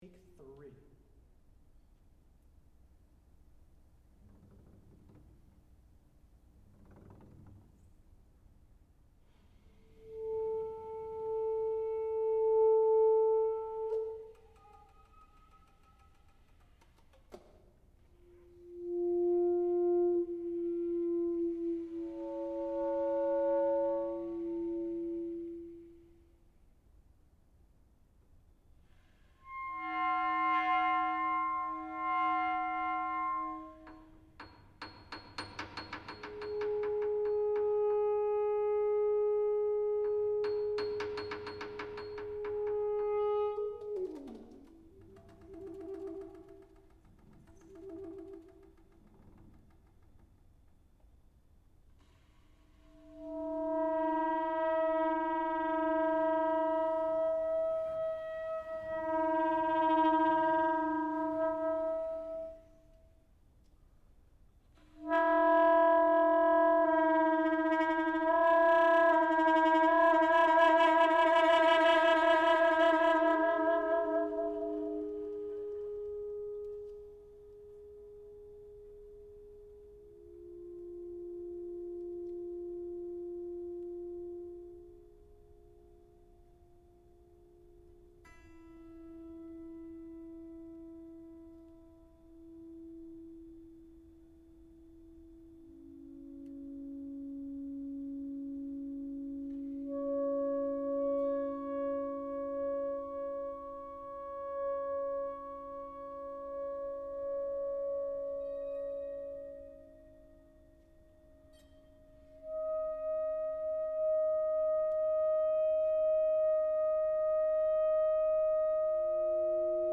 for alto saxophone and piano (12:00)
Preview of studio recording